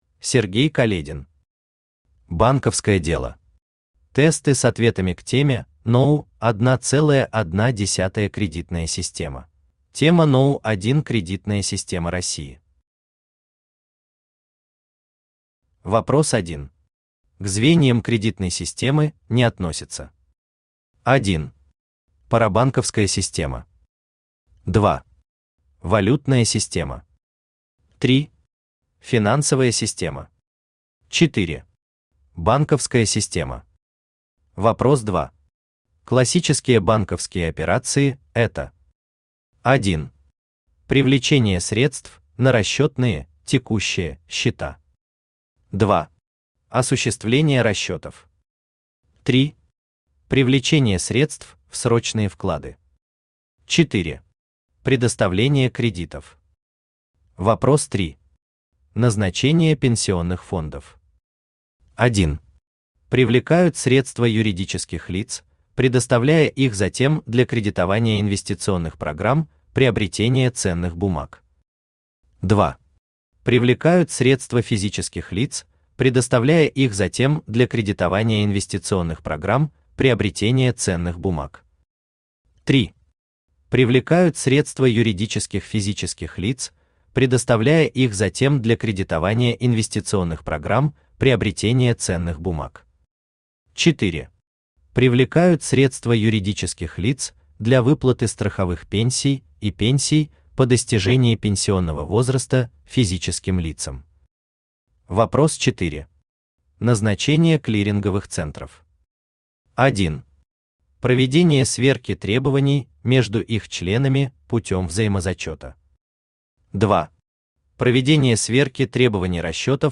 Аудиокнига Банковское дело. Тесты с ответами к теме № 1.1 «Кредитная система» | Библиотека аудиокниг
Aудиокнига Банковское дело. Тесты с ответами к теме № 1.1 «Кредитная система» Автор Сергей Каледин Читает аудиокнигу Авточтец ЛитРес.